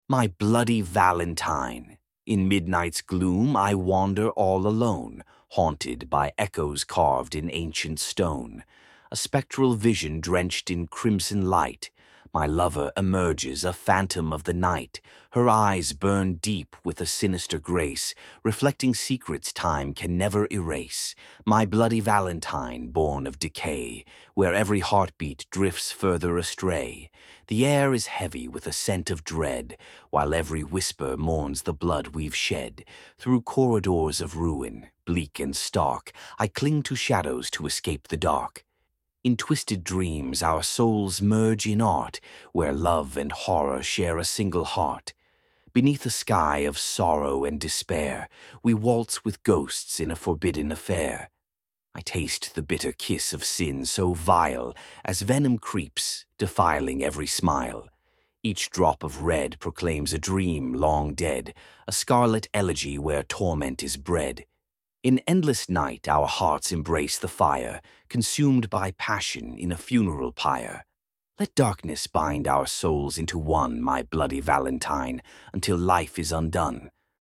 Your reading voice enhances the meaning of each line and succeeds in making it so realistic that you seem to be speaking to your love instead of writing this for her.